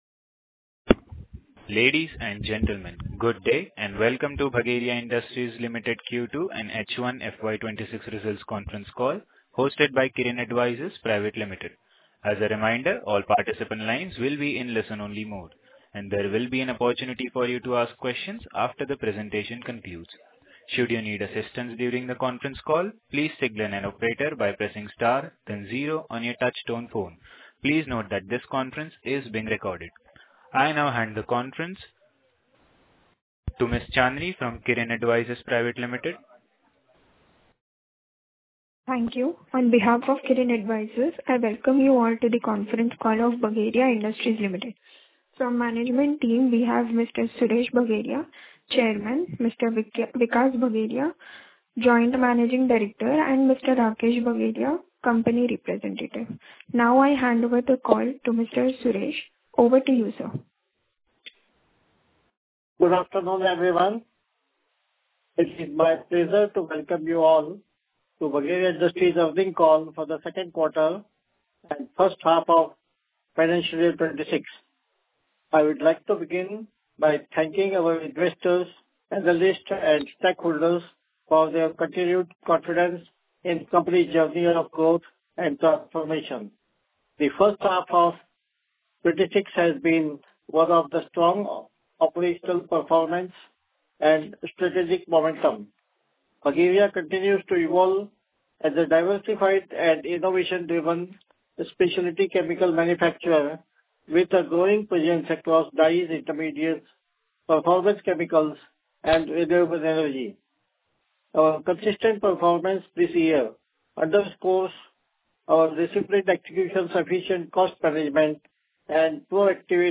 Audio Recording of Investor Meet 28.10.2025
Bhageria-Industries-Q2-H1-FY26-Earnings-Call-Audio.mp3